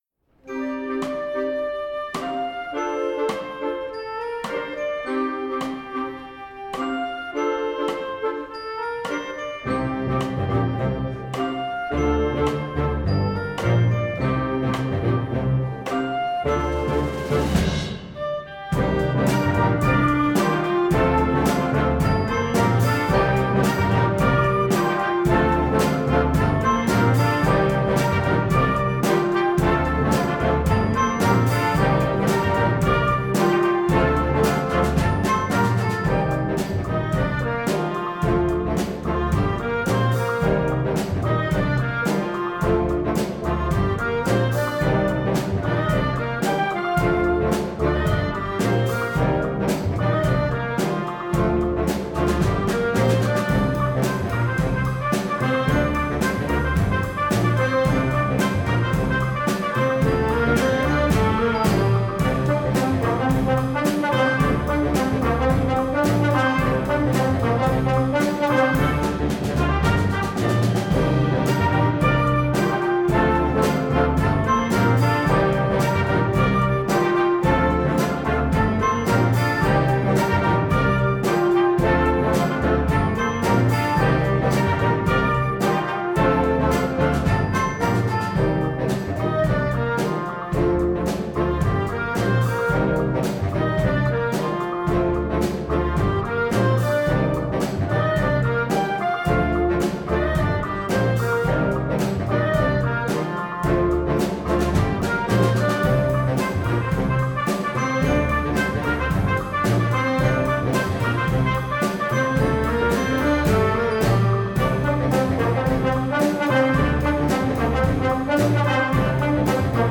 Gattung: Moderner Einzeltitel für Jugendblasorchester
Besetzung: Blasorchester
hat den perfekten Groove und eine ansteckende Melodie.